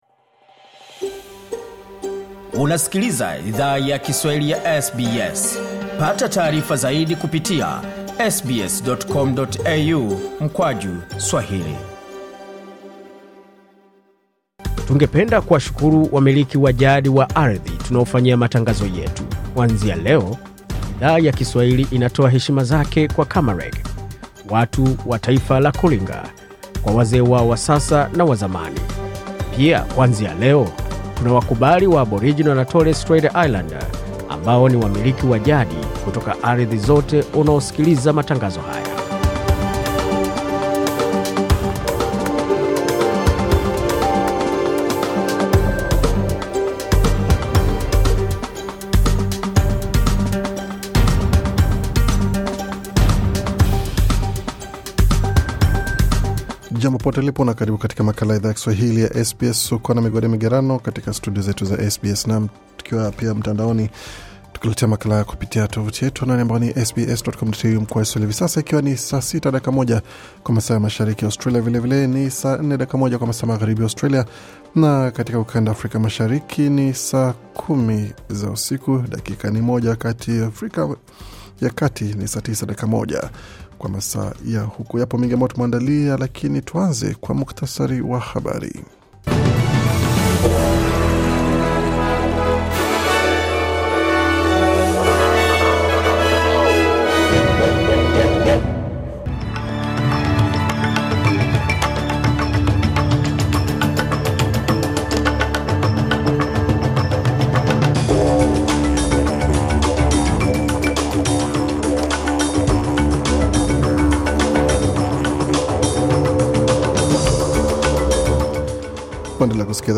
Taarifa ya Habari 16 Februari 2024